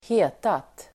Uttal: [²h'e:tat]